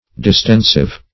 Distensive \Dis*ten"sive\, a.